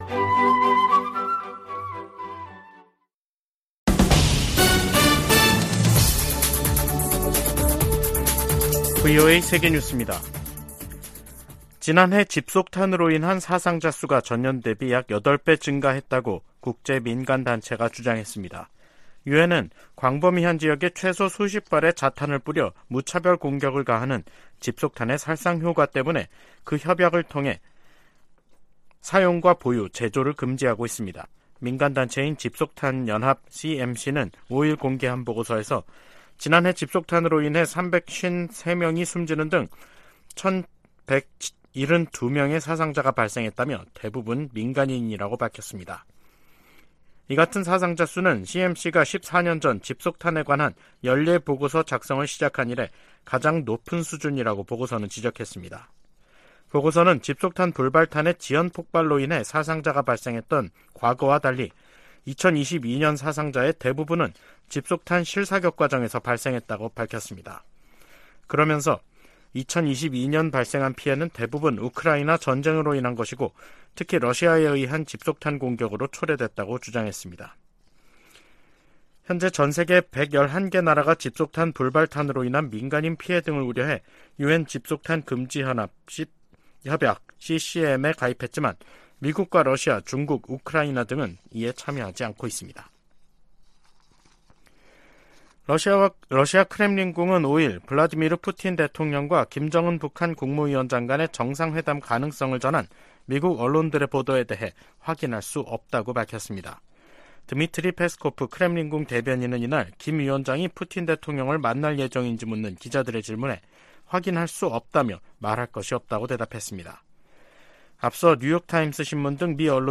VOA 한국어 간판 뉴스 프로그램 '뉴스 투데이', 2023년 9월 5일 3부 방송입니다. 백악관은 북한 김정은 위원장의 러시아 방문에 관한 정보를 입수했다고 밝혔습니다. 미 국무부는 북한과 러시아의 연합 군사훈련 논의 가능성을 비판했습니다.